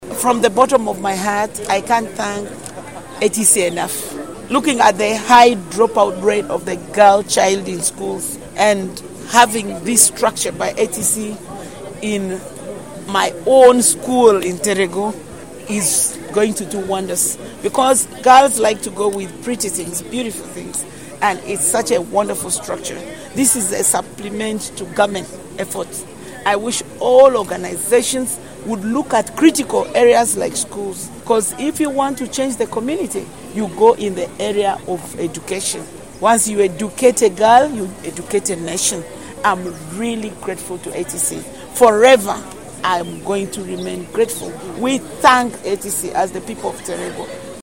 The woman Member of Parliament of Terego district, Rose Obiga